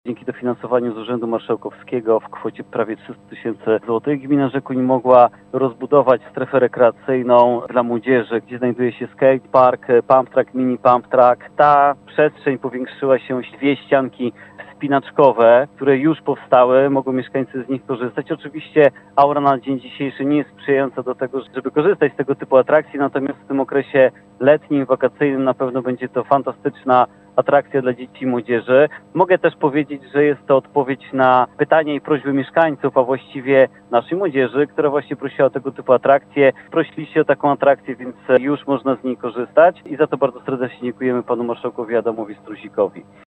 Podkreśla Bartosz Podolak, wójt gminy Rzekuń.